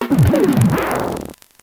Cri de Darumacho Mode Normal dans Pokémon Noir et Blanc.